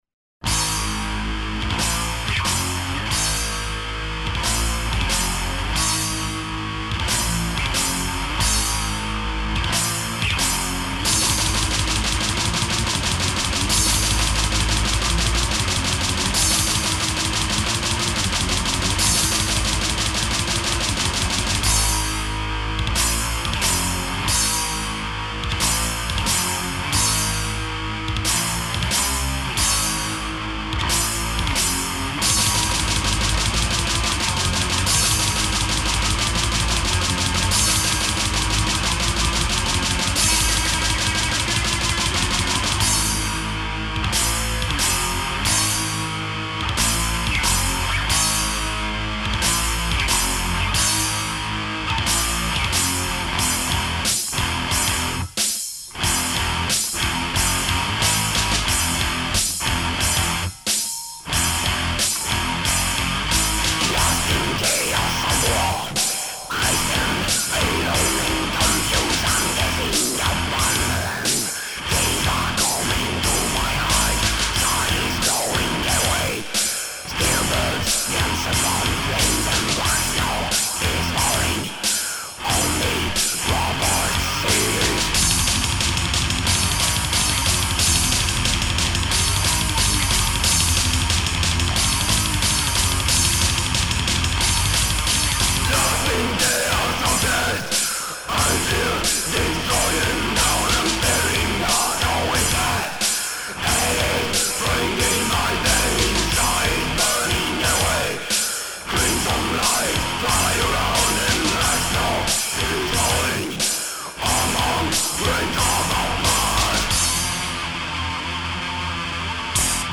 C'est une version proche de celle de la première démo.